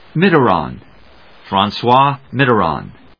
音節Mit・ter・rand 発音記号・読み方
/mìːterάːŋ(米国英語), Fran・çois frɑːnswάː(英国英語)/